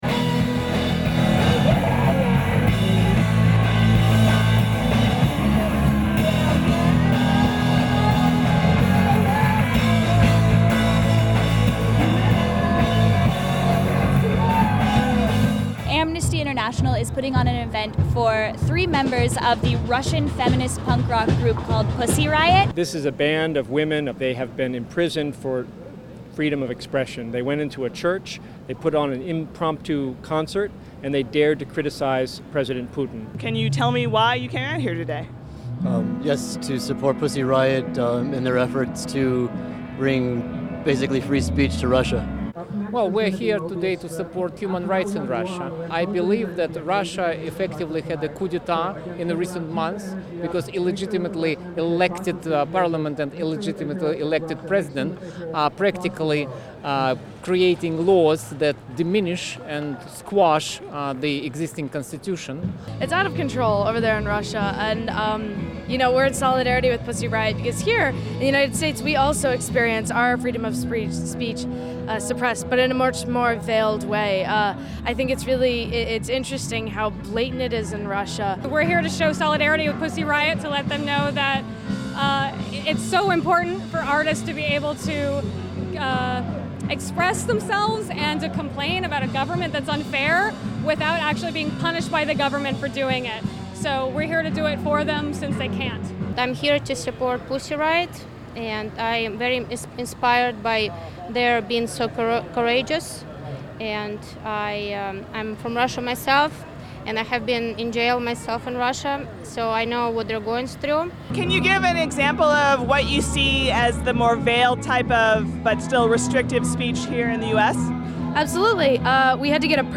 The plight of Pussy Riot has provoked international attention — and pressure for lenience — as the women face three to seven years in prison. On August 10, Reason TV headed down to the Solidarity Concert for Pussy Riot, right across from the Russian embassy.